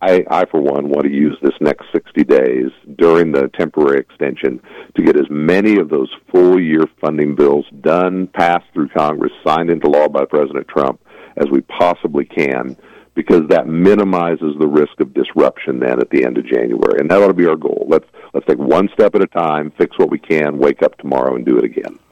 Kansas Congressman Derek Schmidt took some time to update KVOE listeners on agriculture policy and overall budgeting as part of his monthly visit on the KVOE Morning Show on Friday.
Schmidt appears on KVOE’s Morning Show during the last Friday of the month’s Newsmaker 2 segment.